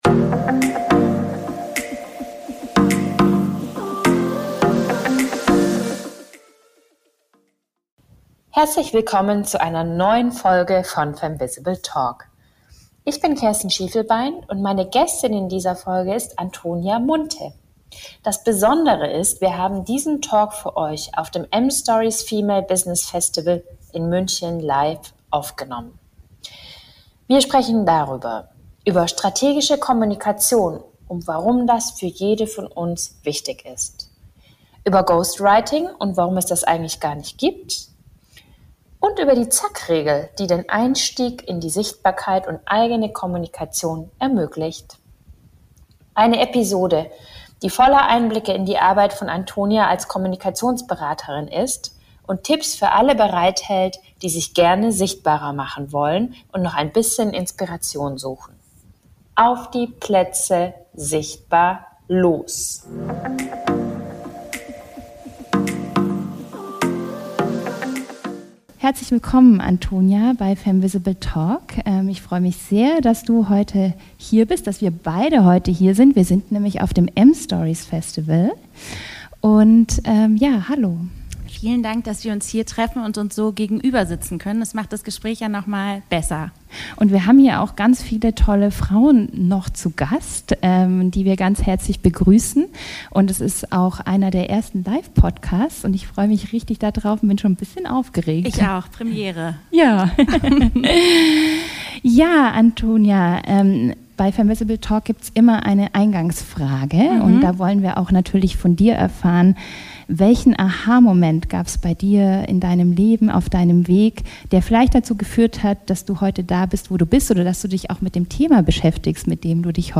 Das besondere an diesem Talk ist, dass wir ihn auf dem MStories Female Business Festival 2024 live aufgenommen.